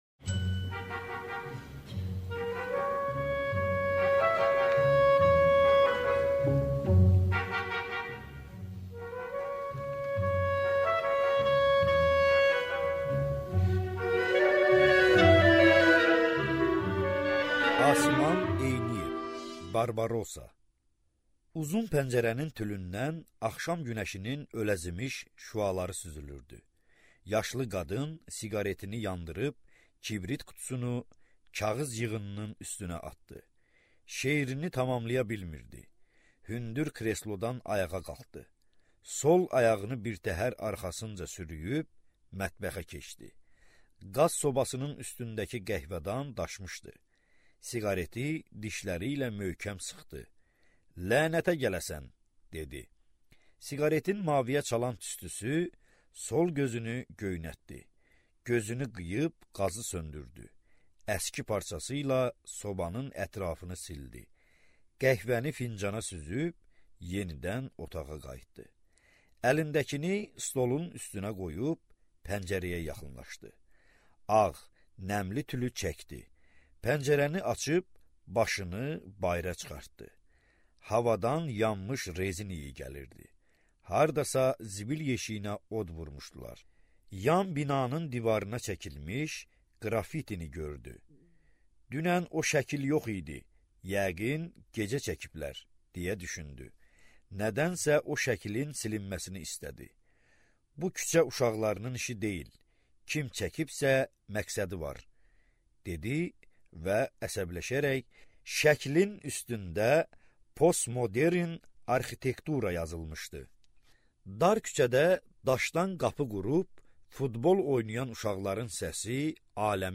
Аудиокнига Sahil (hekayələr) | Библиотека аудиокниг
Прослушать и бесплатно скачать фрагмент аудиокниги